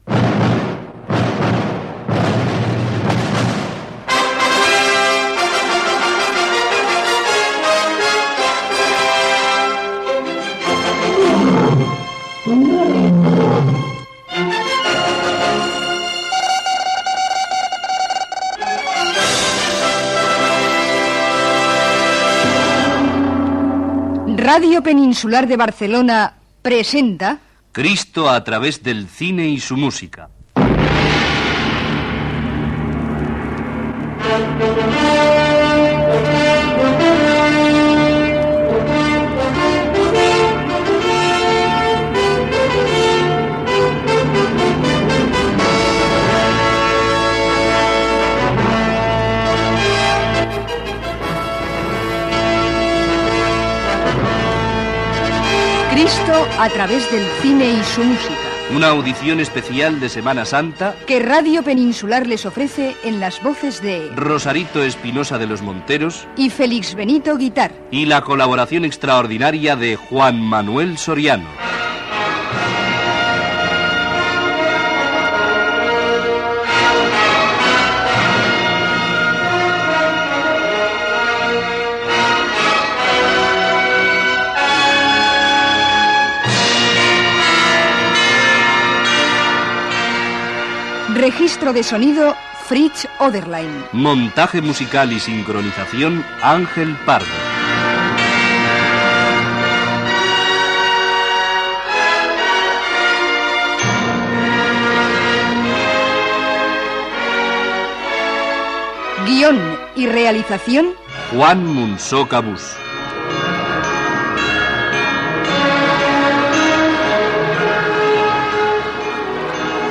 Audició especial de Setmana Santa.
Careta del programa, presentació del tema de la Passió de Crist al cinema